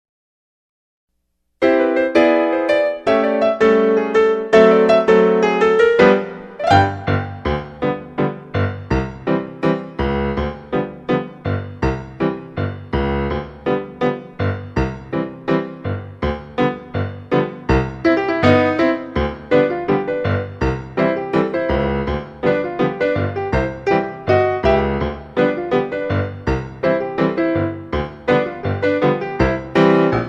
Instrumental Tracks.
▪ The full instrumental track